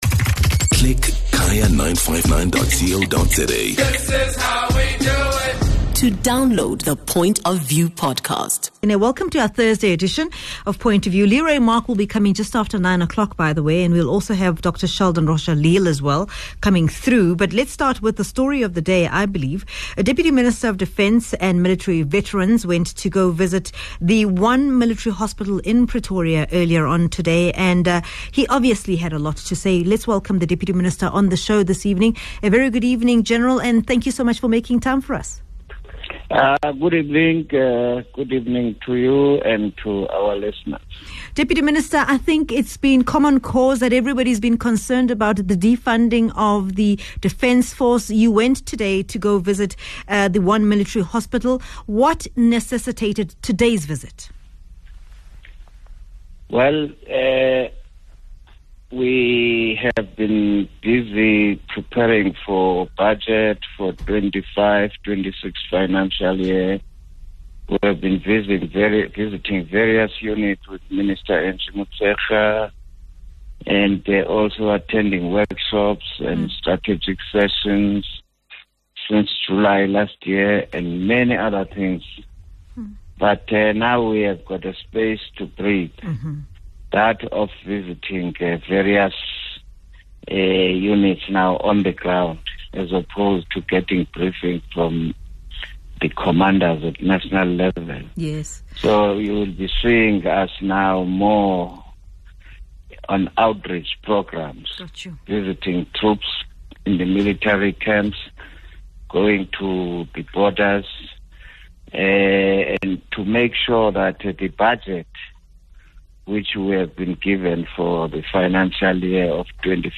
Phemelo speaks to Holomisa about what he found during his visit.